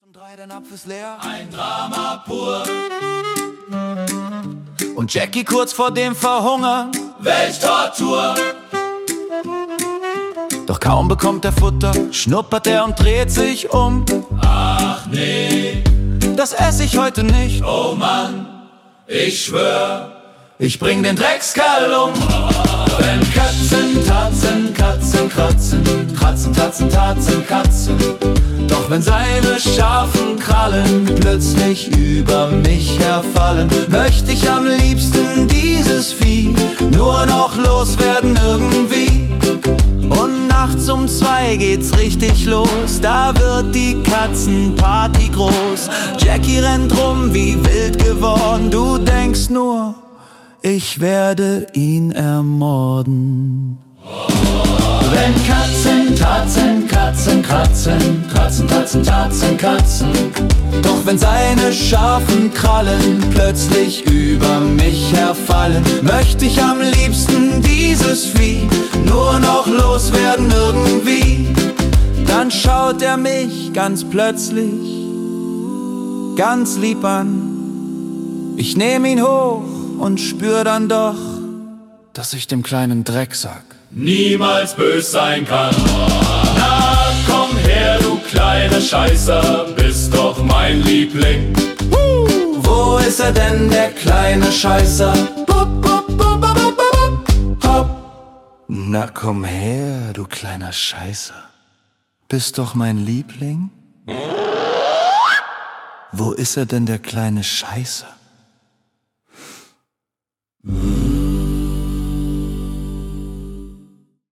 (Comedy-Jazz)